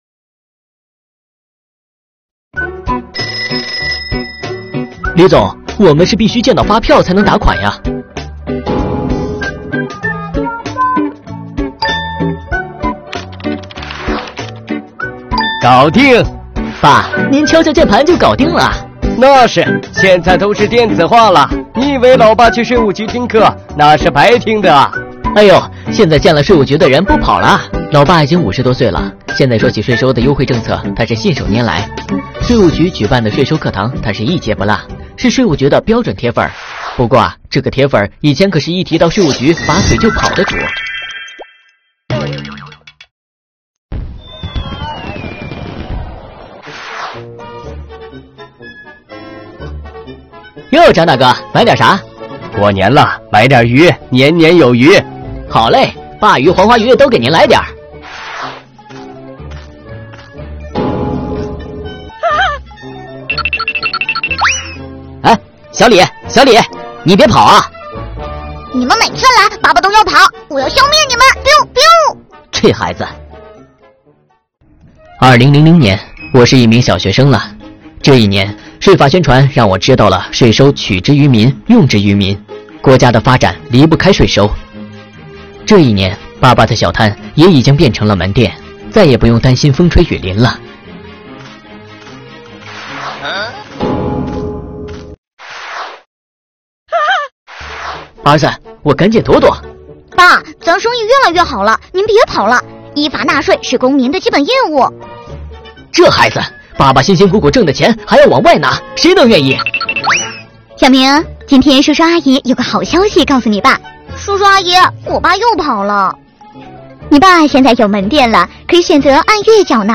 本作品通过李村大集上一个海鲜小摊的发展，讲述了税收征管方式的变革以及纳税人依法纳税意识的逐步提升。作品采用动画的形式，制作精良，人物鲜活，故事情节真实有趣，音效轻松活泼，趣味性十足。